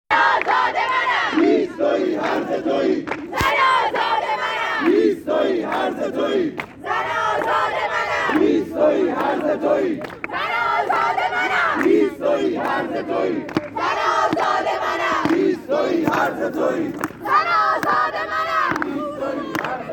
🟥◾تجمع دانشجویان دانشگاه نوشیروانی بابل با شعارهای: "اونی که بی‌طرفه مسلما بی‌شرفه" و "هیز تویی هرزه تویی زن آزاده منم".